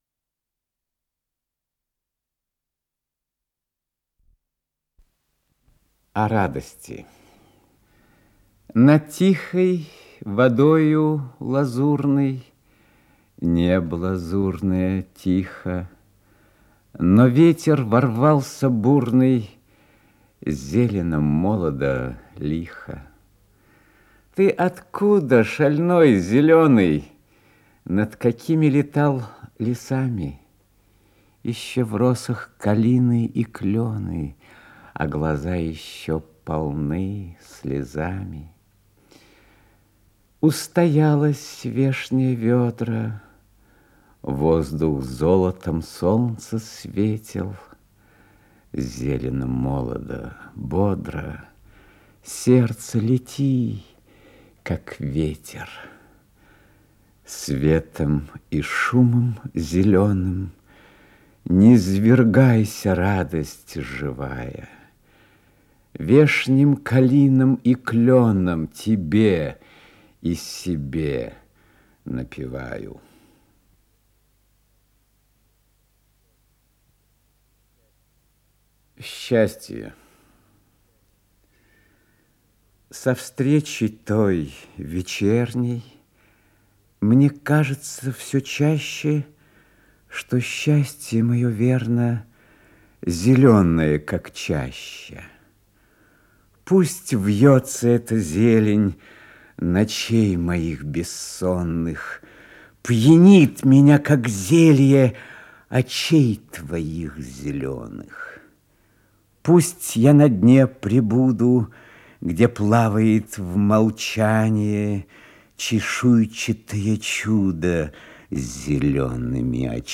Исполнитель: Иннокентий Смоктуновский - чтение
Стихи